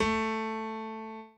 b_pianochord_v100l1o4a.ogg